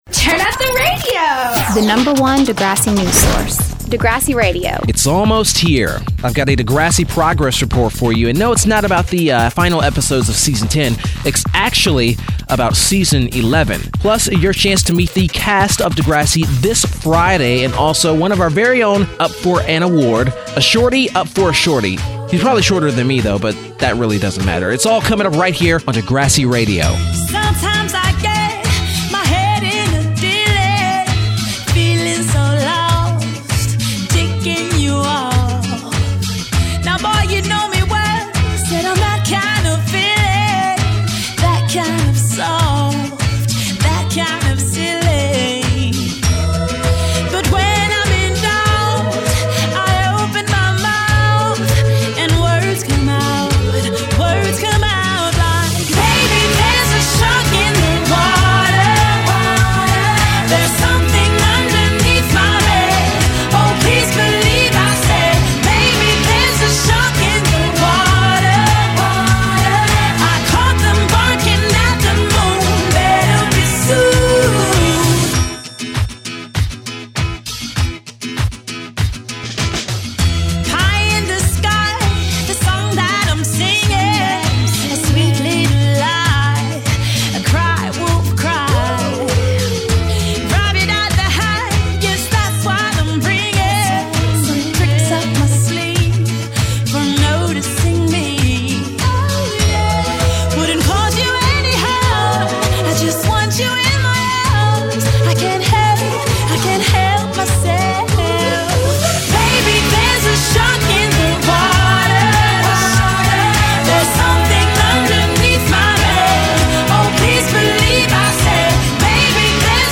It’s a mix of discussing the news content below and music.